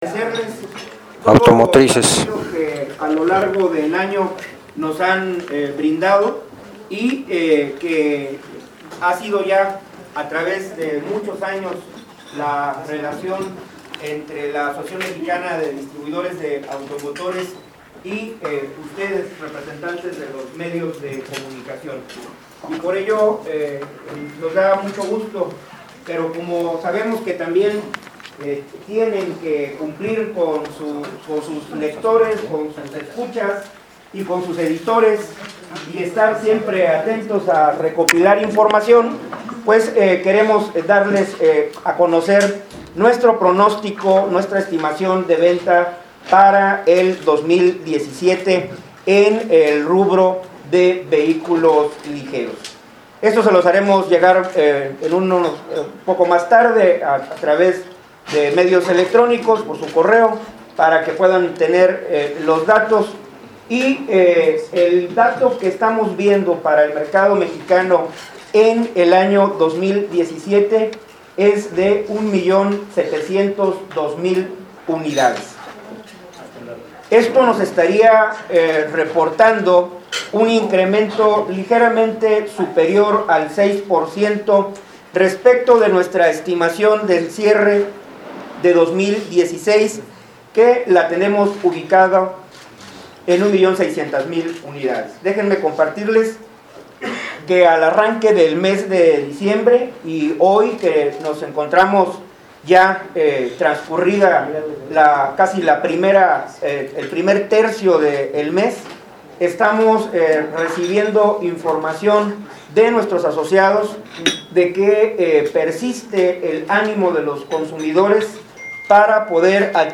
Descarga Boletín AMDA 161209 aquí Descarga foto 1 en ALTA resolución aquí Descarga foto 1 en baja resolución aquí Descarga audio completo de la conferencia aquí Descarga cifras aquí